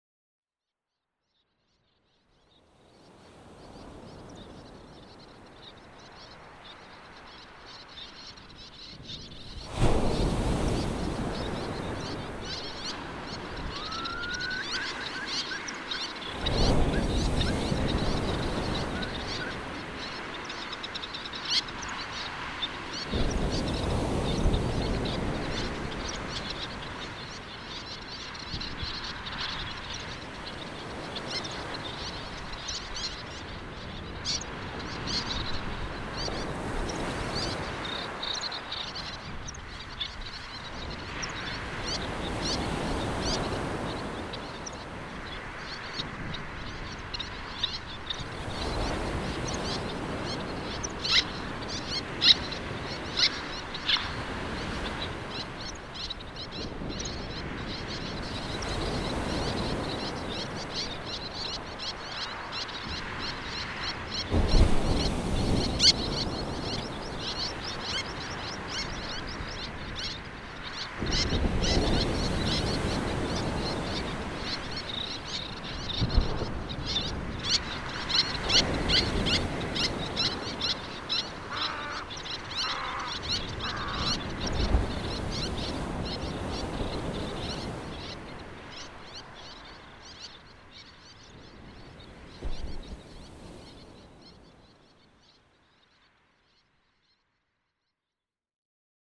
Winterton on Sea. Any niche available? (creative)
Little Tern Sternula a. albifrons
Little Egret Egretta g. garzetta
Eurasian Curlew Numenius a. arquata
Meadow Pipit Anthus pratensis
winterton-on-sea_bounce.mp3